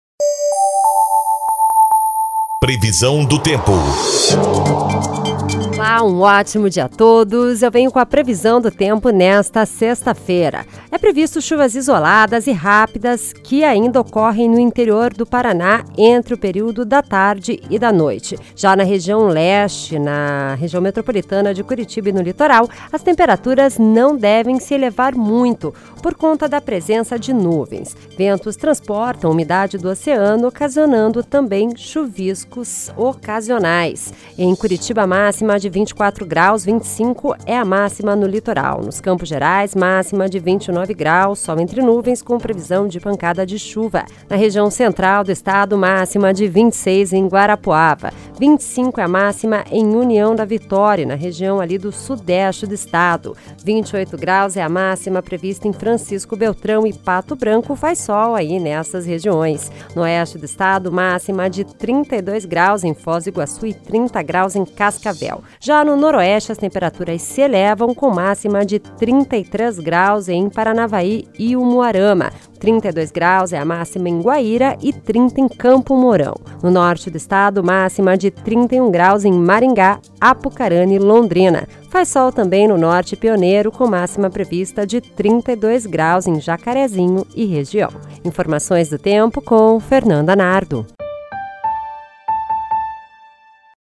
Previsão do Tempo (05/01)